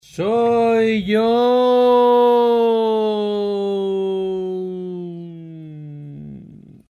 Grabamos a 44100 muestras por segundo, con sus valores de las muestras almacenados en 16 bits, en  estéreo.
Primero grabamos con un buen micrófono RØDE, en formato wav (PCM, sin pérdidas), y luego pasamos a mp3.
0.1 'Soy yo...'  1/19. grito ´